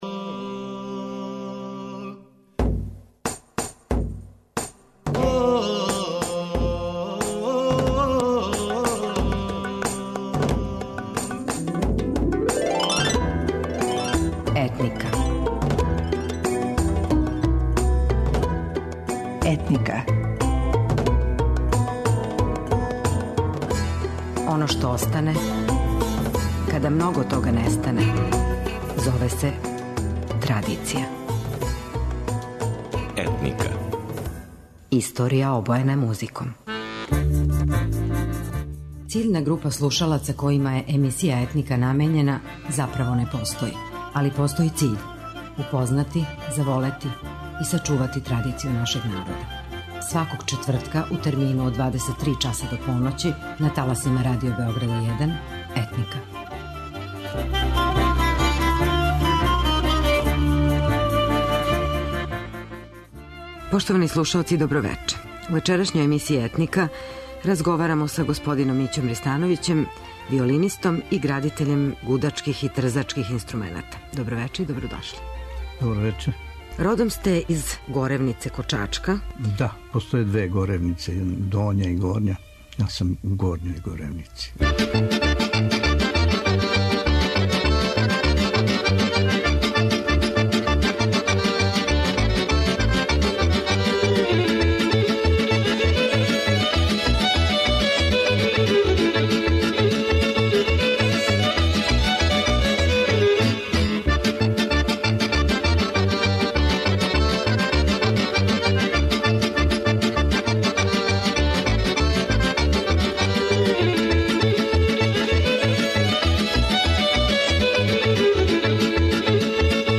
инструменталиста на виолини, композитор и градитељ гудачких и трзачких инструмената.